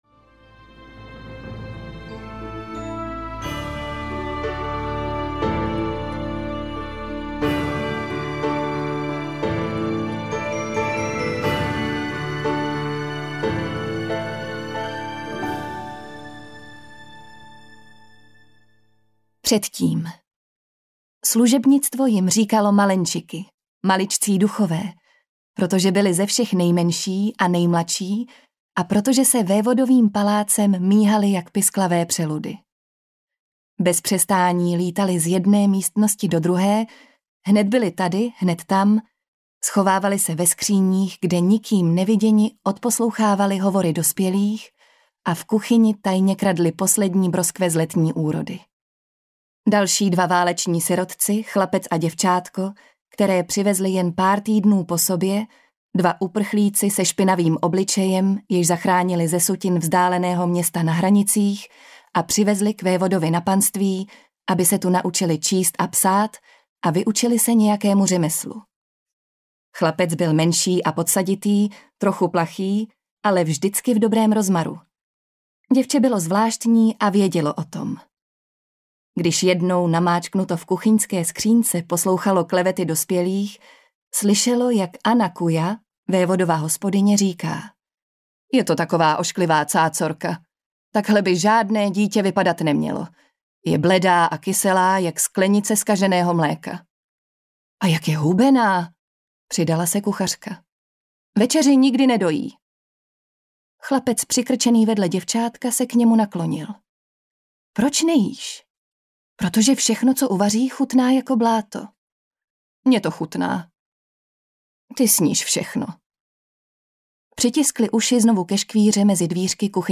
Griša - Světlo a stíny audiokniha
Ukázka z knihy